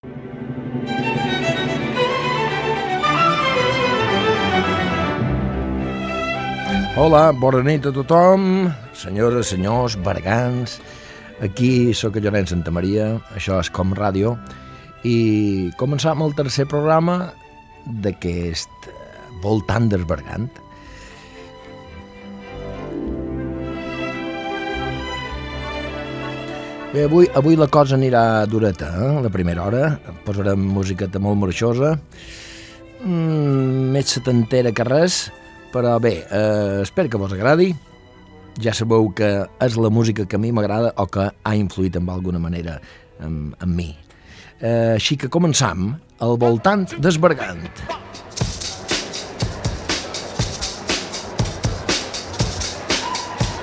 Identificació del programa, presentació inicial i tema musical
Musical